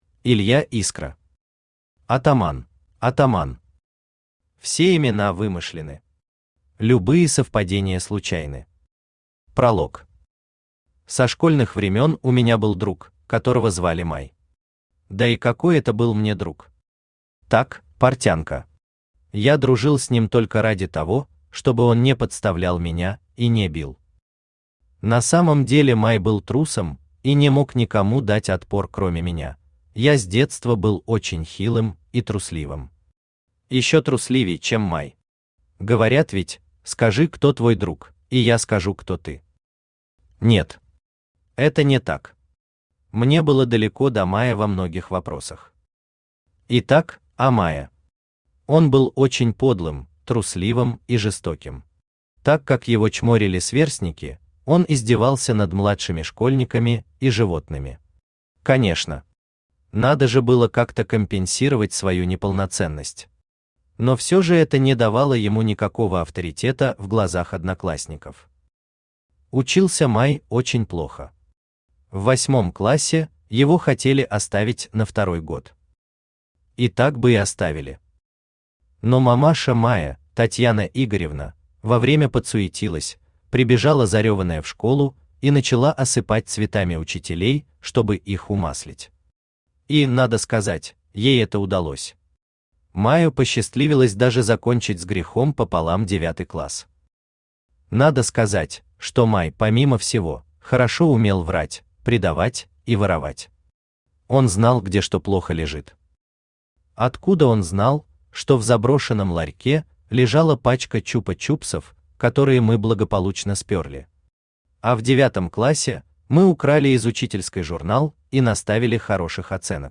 Аудиокнига Атаман | Библиотека аудиокниг
Aудиокнига Атаман Автор Илья Искра Читает аудиокнигу Авточтец ЛитРес.